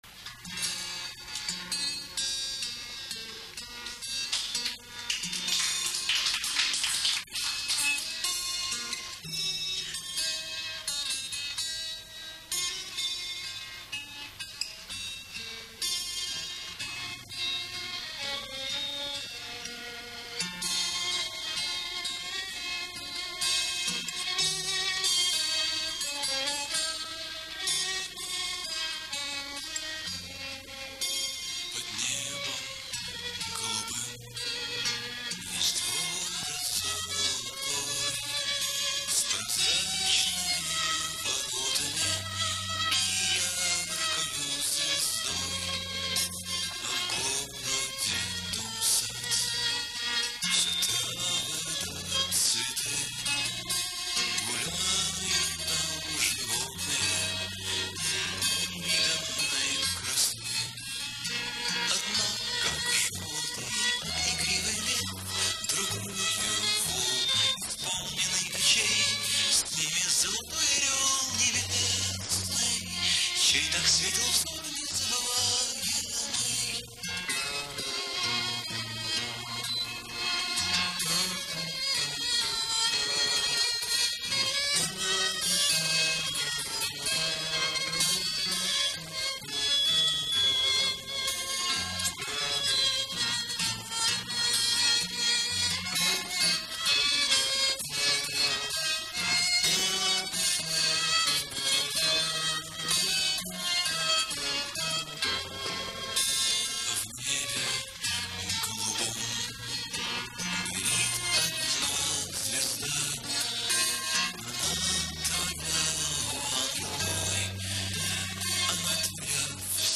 Студенческий клуб "Корабел" Ленинградского